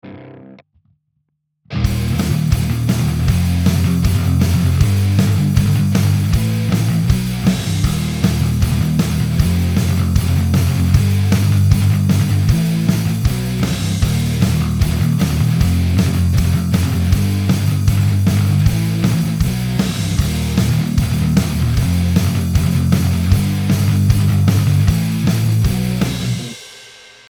Metall (Bias Fx)